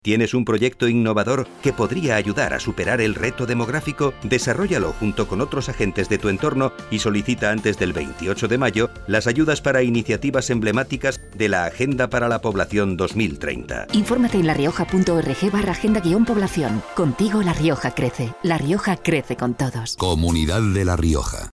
Cuña radiofónica